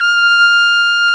Index of /90_sSampleCDs/Roland LCDP12 Solo Brass/BRS_Piccolo Tpt/BRS_Picc.Tp 2 St